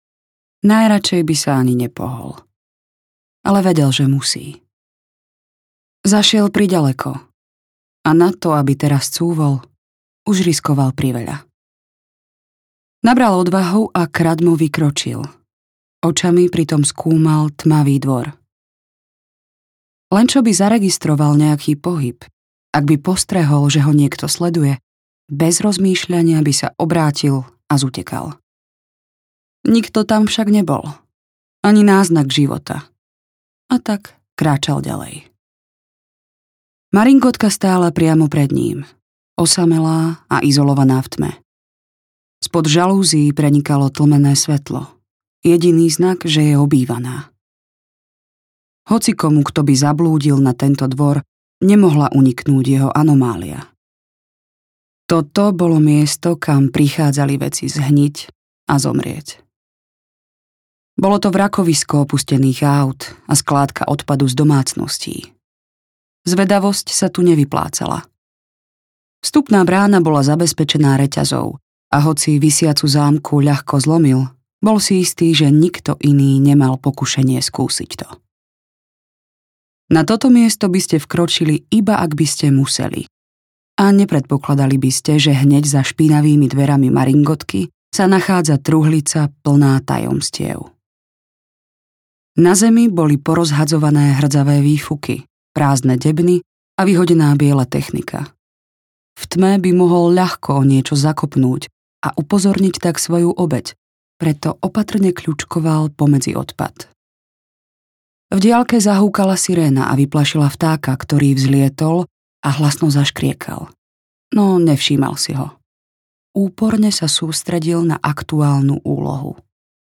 Vadí - Nevadí audiokniha
Ukázka z knihy